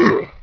painshrt.wav